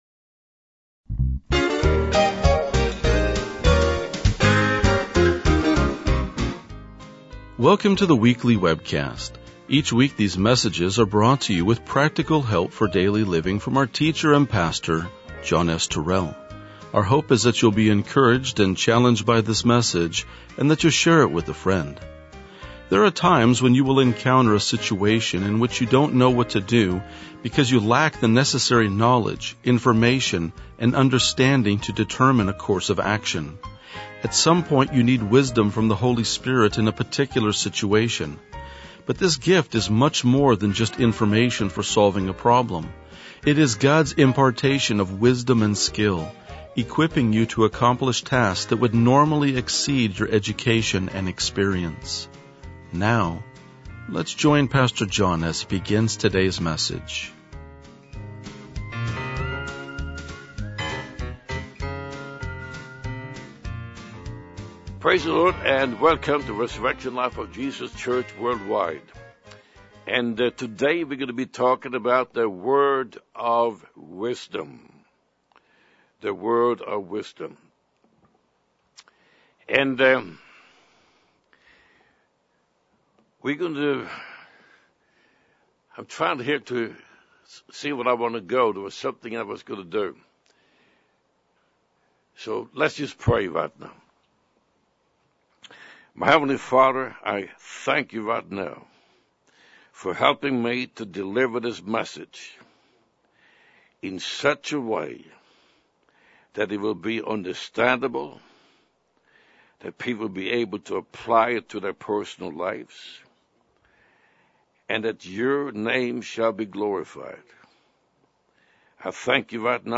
RLJ-2021-Sermon.mp3